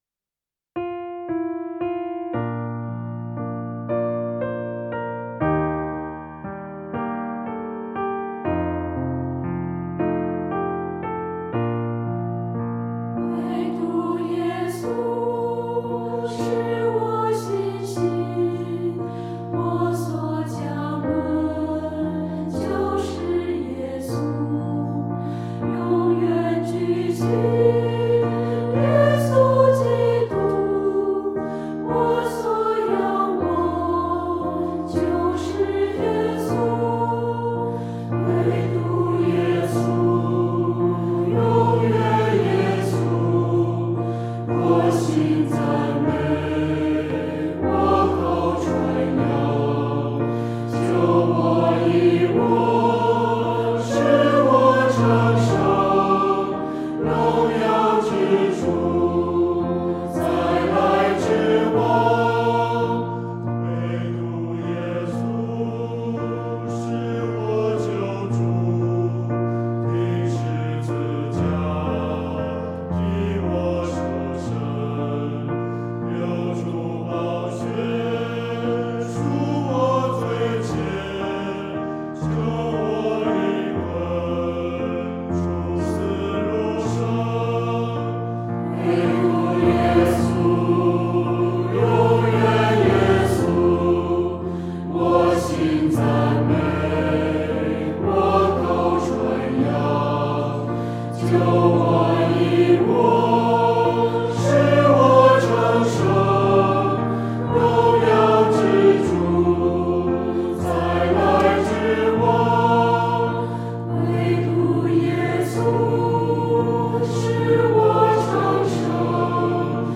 唱诗：惟独耶稣（新229）